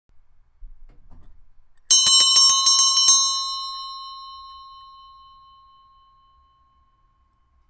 attention-bell.mp3